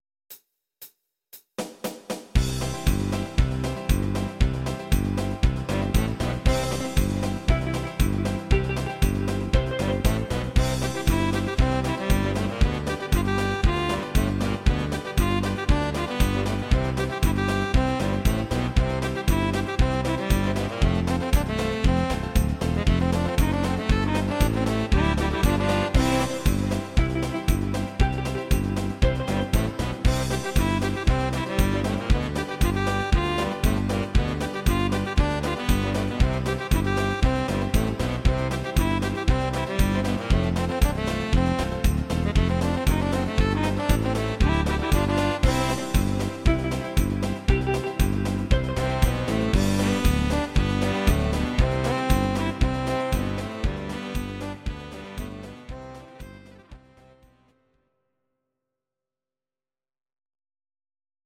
Please note: no vocals and no karaoke included.
Your-Mix: Instrumental (2073)